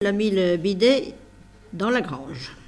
Mémoires et Patrimoines vivants - RaddO est une base de données d'archives iconographiques et sonores.
Enquête Arexcpo en Vendée
Catégorie Locution